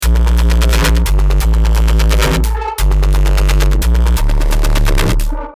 ❇ Ready for BASS HOUSE, TRAP, UKG, DRUM & BASS, DUBSTEP and MORE!
RH - Antiscipate [Dmin] 174BPM
RH-Antiscipate-Dmin-174BPM.mp3